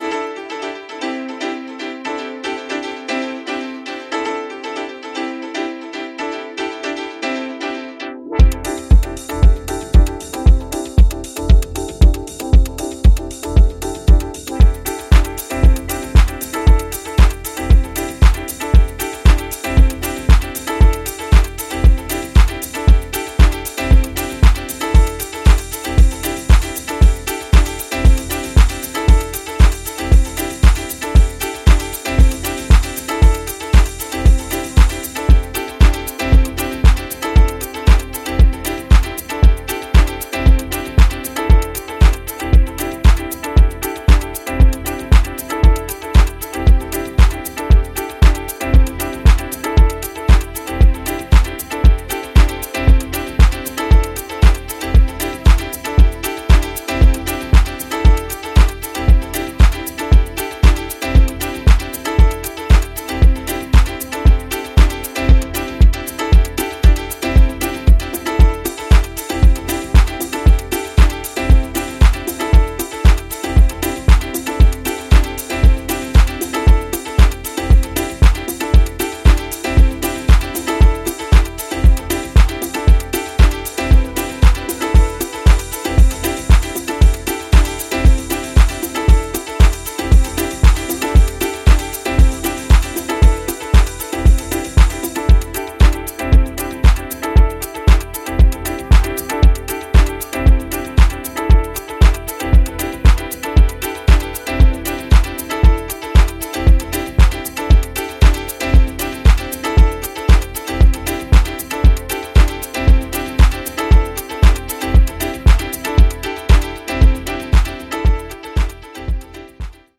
ラフな質感とメランコリーがフロアに浸透していく、非常にエッセンシャルな内容です！